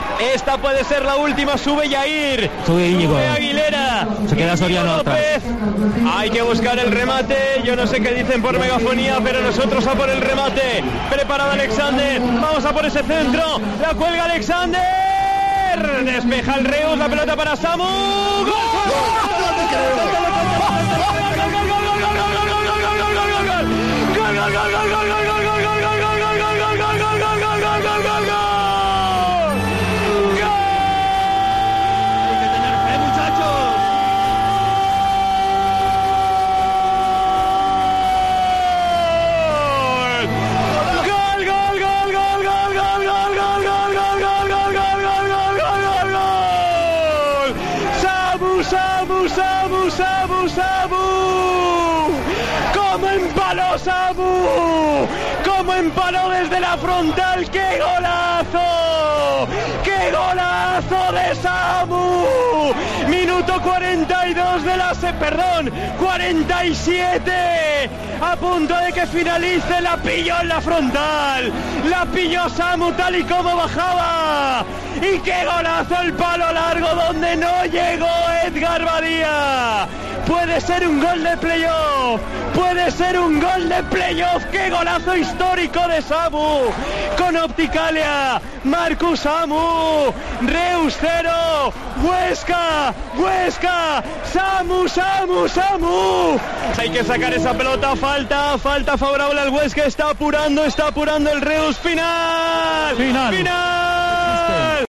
Así cantamos el gol de Samu Sáiz en Reus